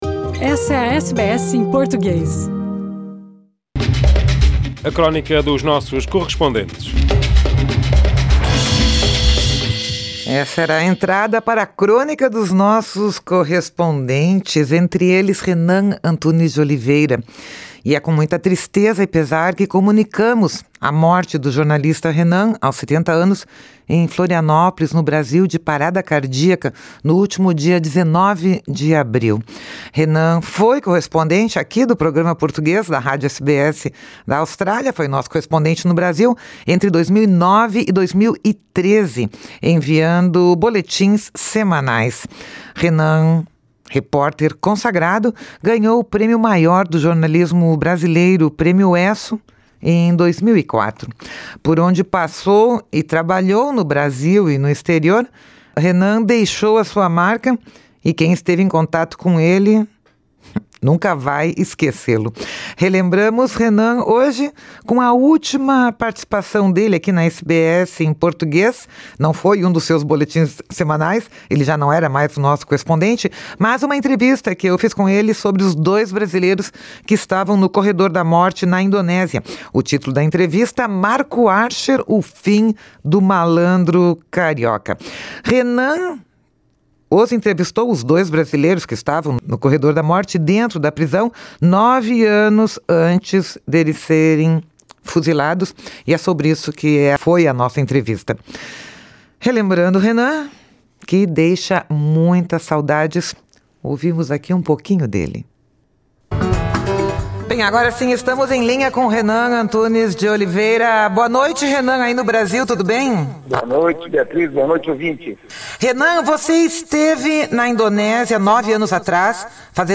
SBS em Português